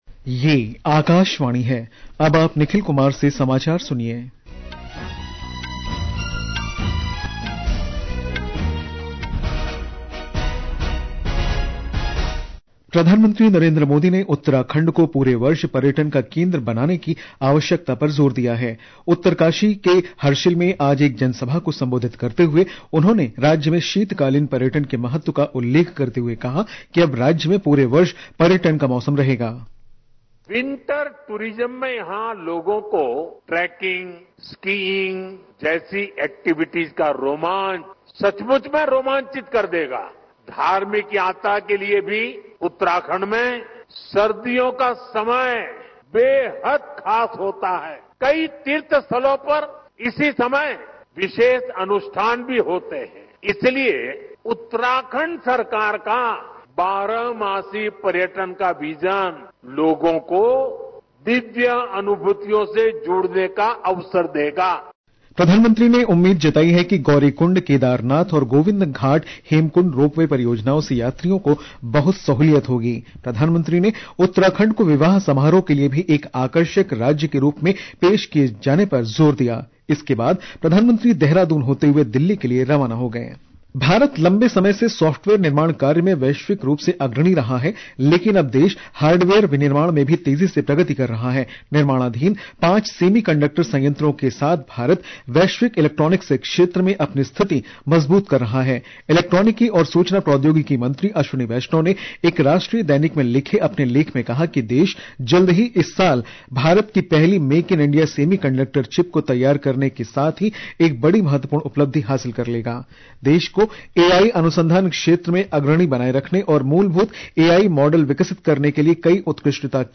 प्रति घंटा समाचार | Hindi